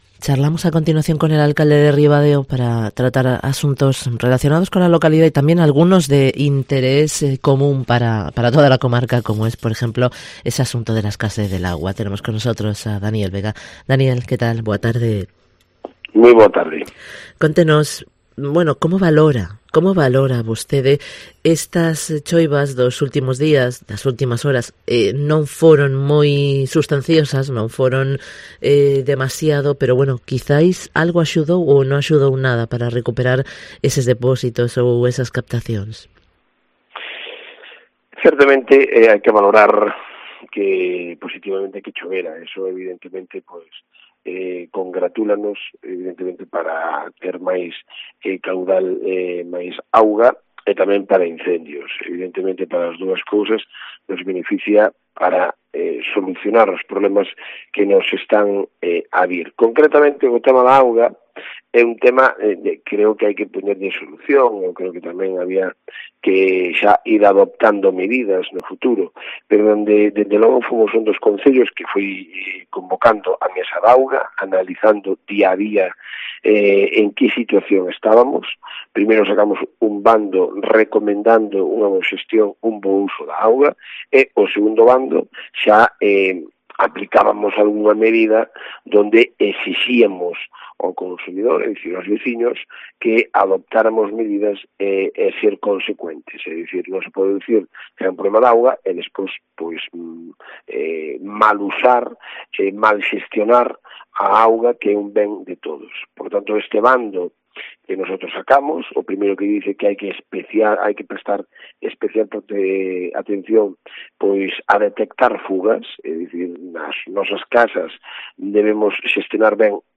Dani Vega, alcalde de Ribadeo, informa sobre la sequía, asuntos de pleno y temas de actualidad